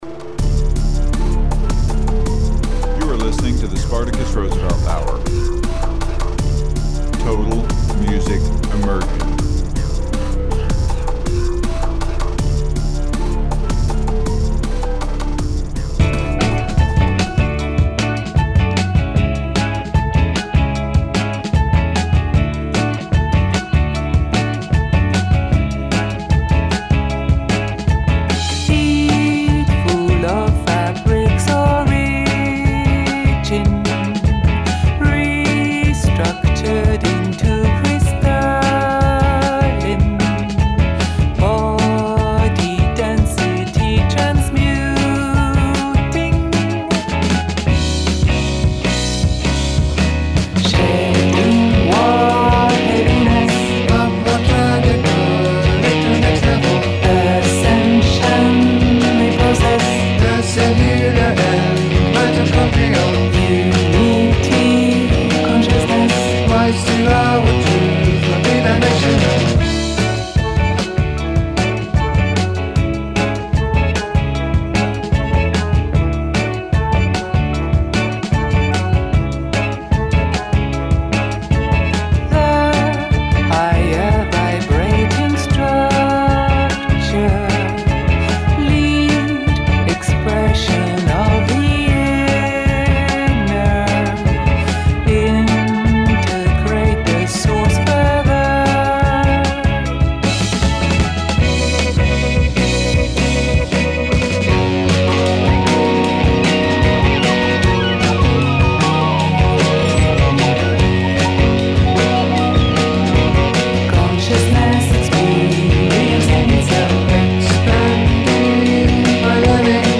> Strange noises all around this episode.